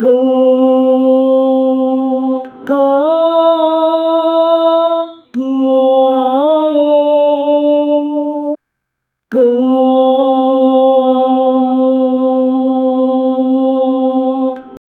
man-singing-aaaaaaah-loop-qgzd7zd3.wav